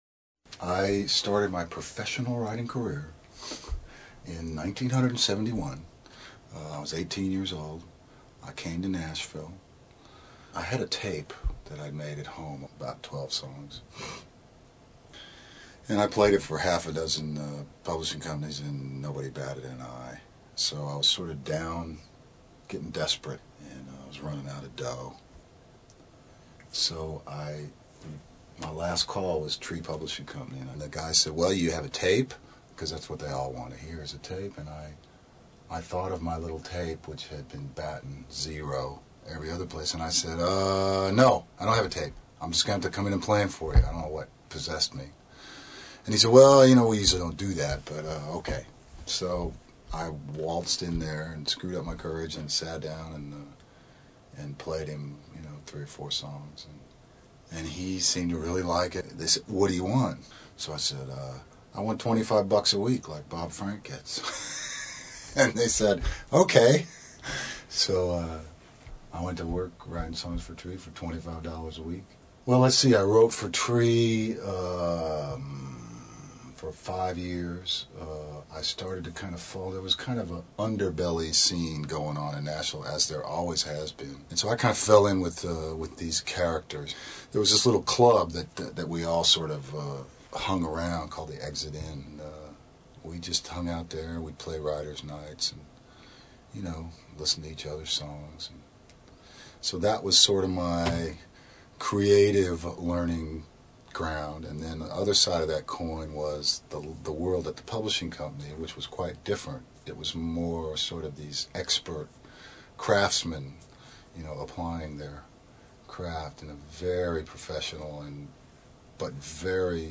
John Hiatt Interview